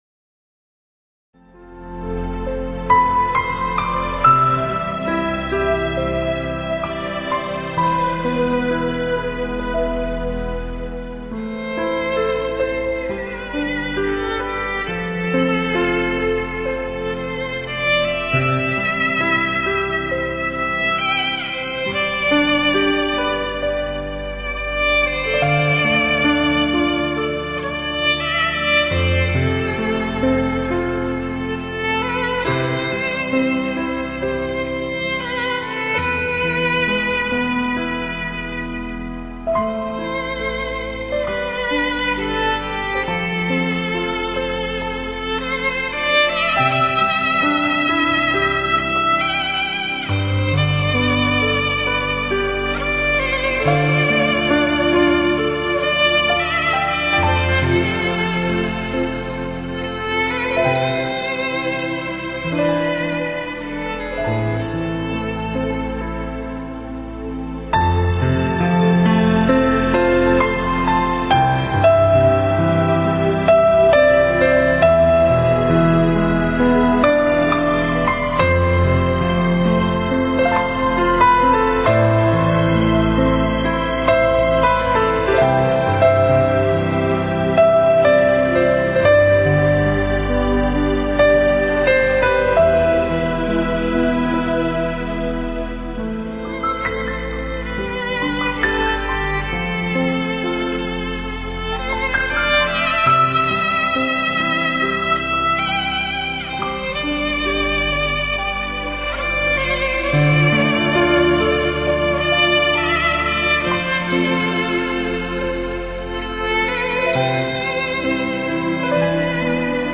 [6/4/2009]爱并忧切 伤并快乐：《带你飞到月亮上》（日本小提琴曲） 激动社区，陪你一起慢慢变老！